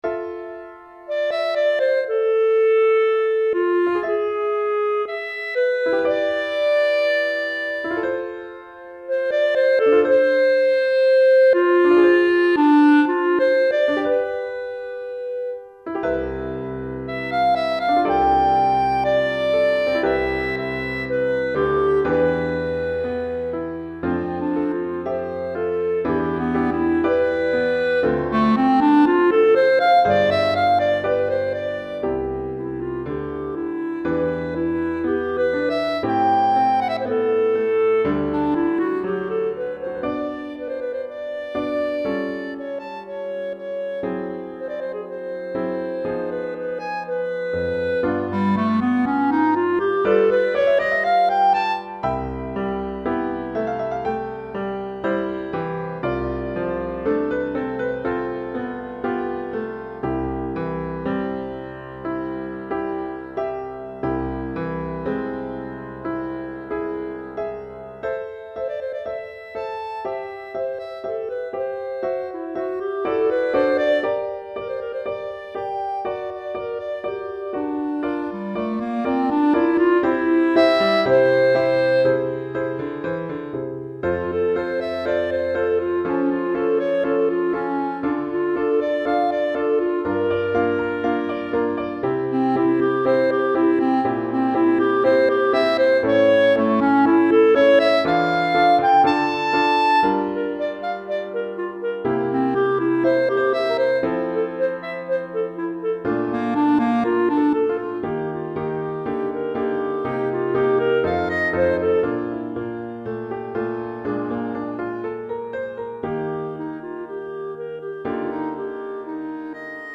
Pour clarinette et piano DEGRE CYCLE 2